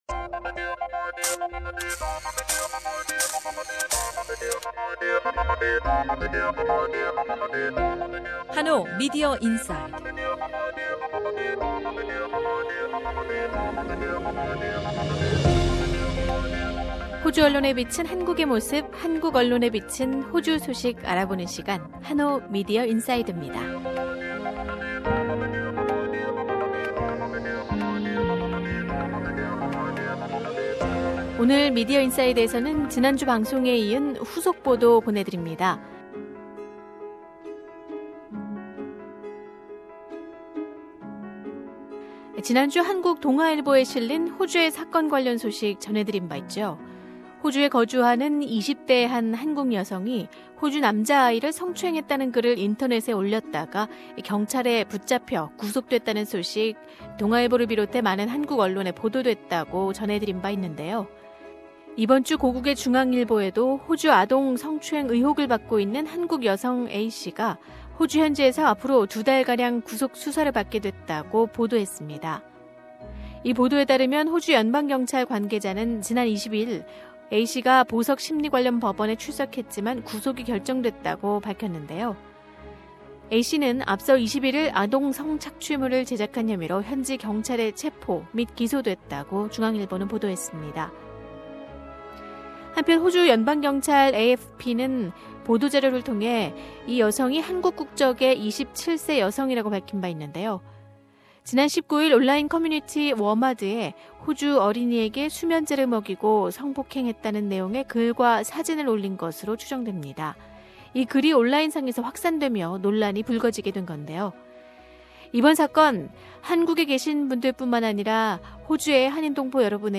단독 인터뷰: 다윈의 아동학대물 제작 혐의 여성체포 사건의 제보자